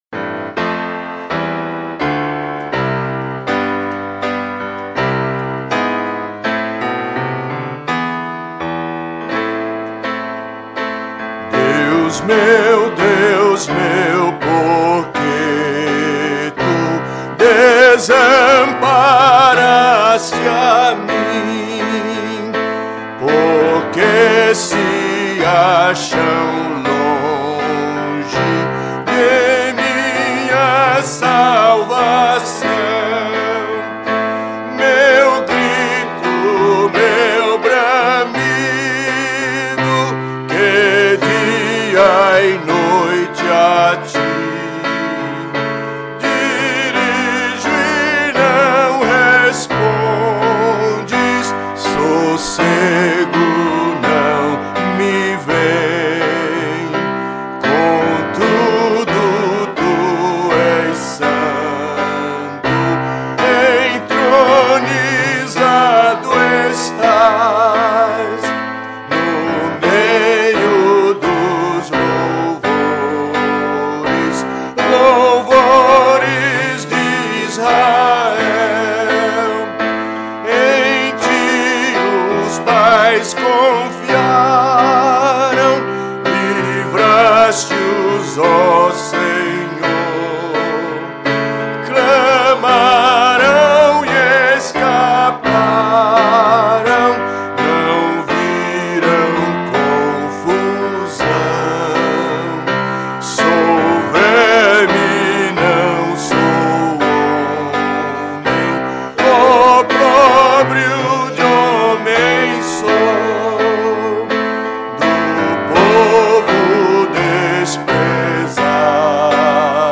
Salmos metrificados para o canto em língua portuguesa
salmo_22B_cantado.mp3